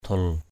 /d̪ʱul/ (Skt.